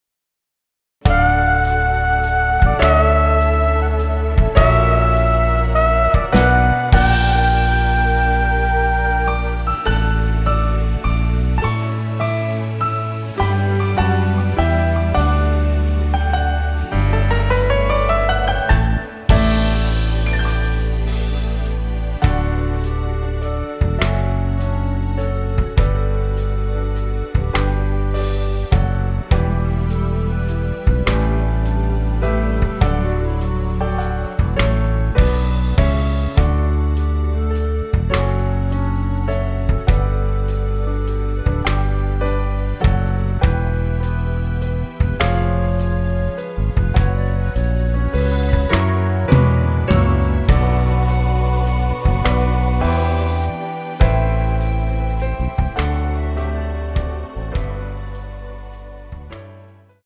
원키에서(-5)내린 멜로디 포함된 MR입니다.
앞부분30초, 뒷부분30초씩 편집해서 올려 드리고 있습니다.
중간에 음이 끈어지고 다시 나오는 이유는